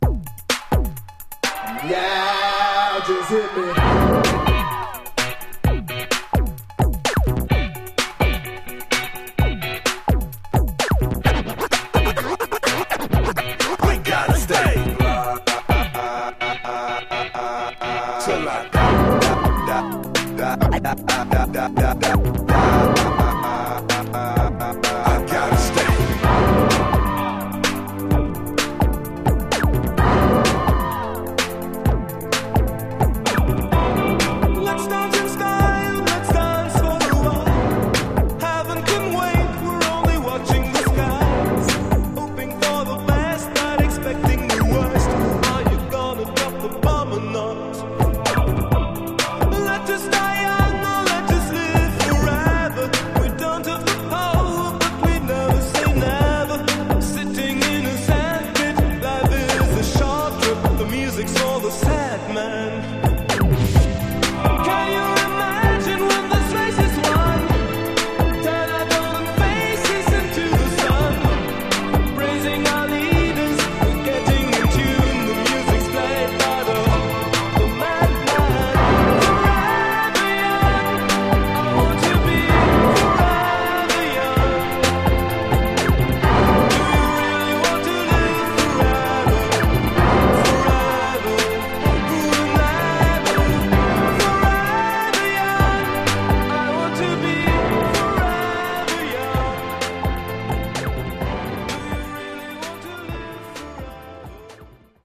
128 bpm
Clean Version